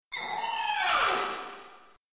squeal upon spawning then try and mate with Rick's back.
splatter_oba.mp3